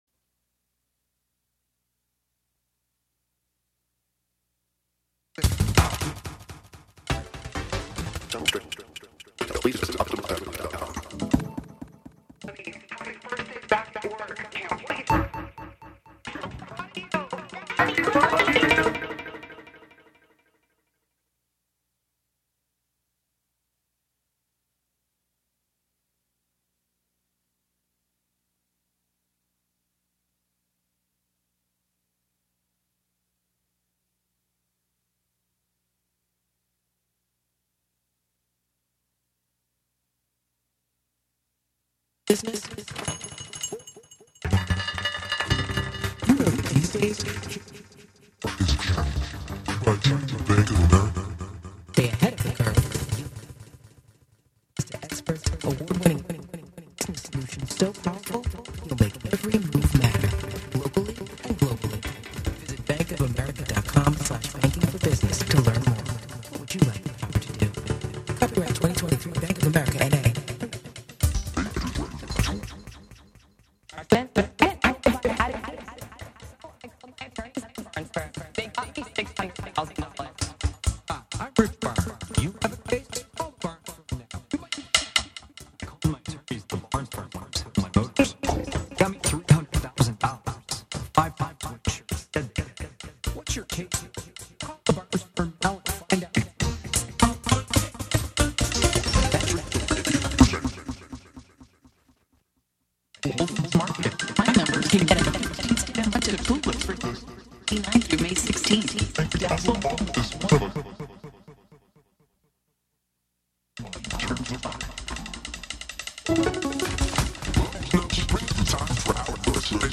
11am Live from Brooklyn, New York
instant techno